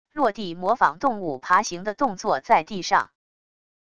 落地模仿动物爬行的动作在地上wav音频